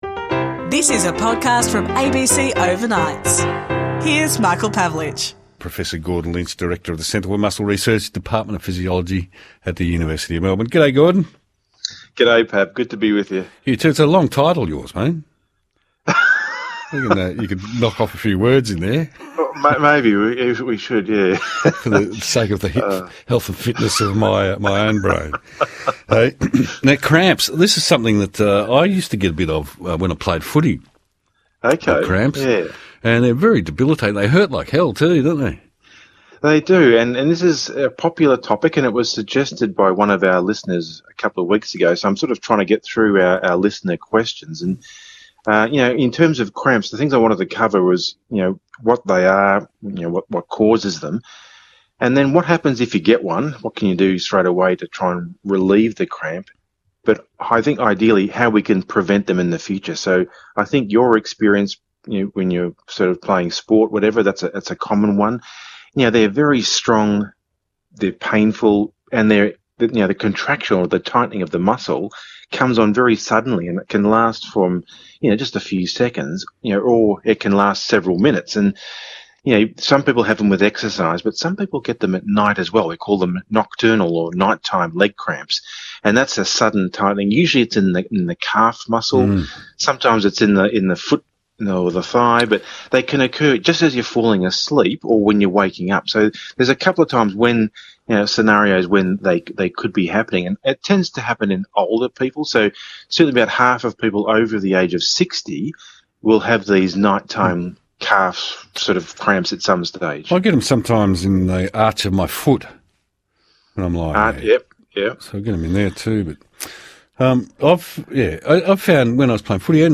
Overnights is heard from 2am to 6am nationally on the ABC.
You'll hear conversations about food, travel, science, music, books, personal finance, sport, film, astronomy, fashion, gardening, relationships, collectables and much more.